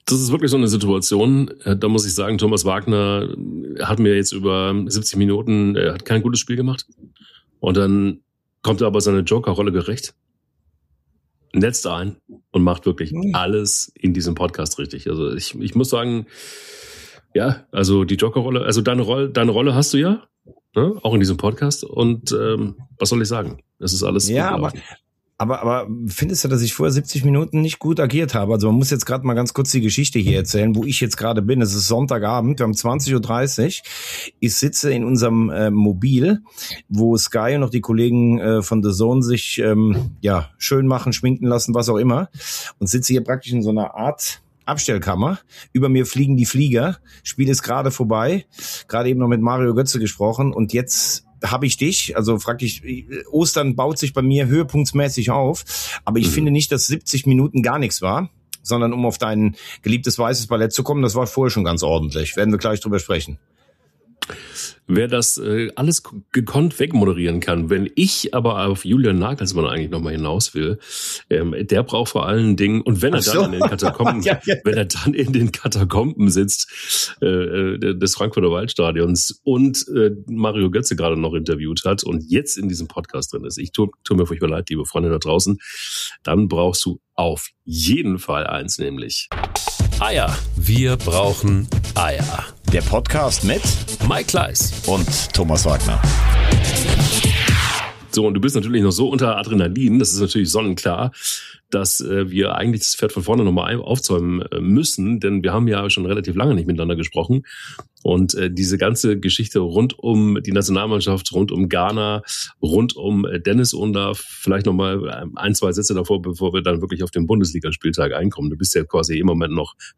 Die ganze Analyse aus der SKY/DAZN Umkleidekabine.